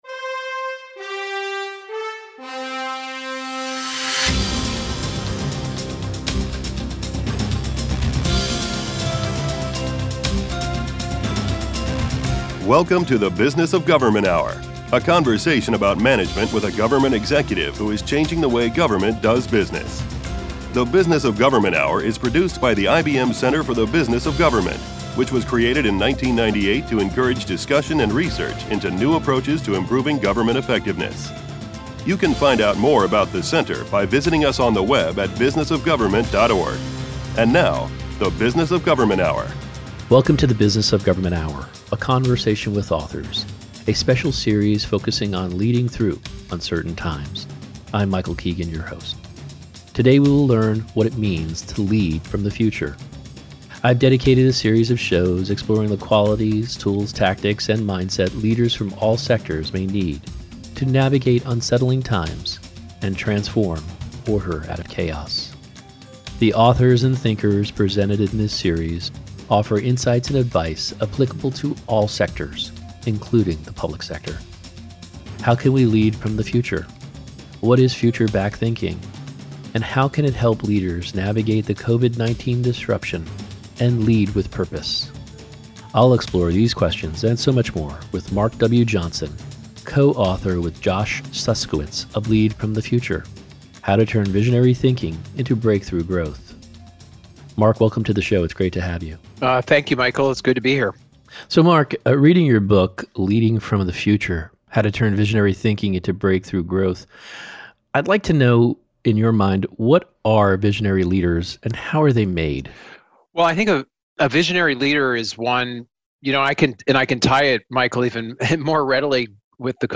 Radio Hour Share Podcast TV Hour The Business of Government Hour Stay connected with the IBM Center Download or Email Listen to the Business of Government Hour Anytime, Anywhere Video not available